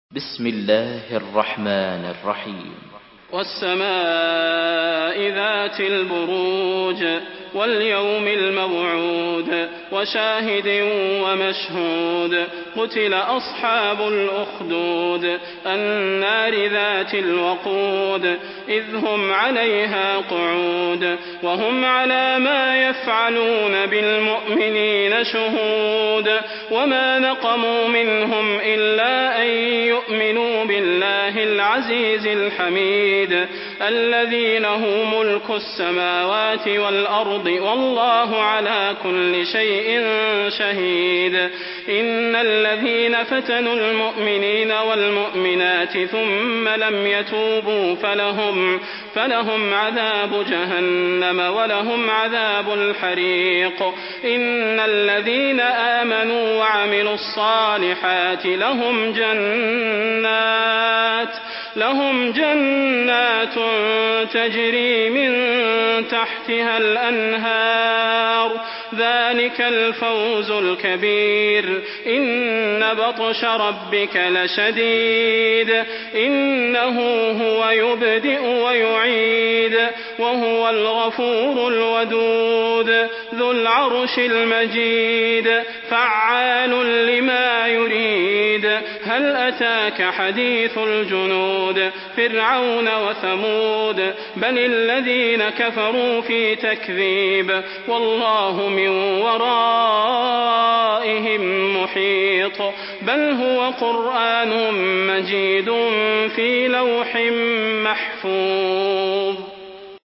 Surah Al-Buruj MP3 by Salah Al Budair in Hafs An Asim narration.
Murattal Hafs An Asim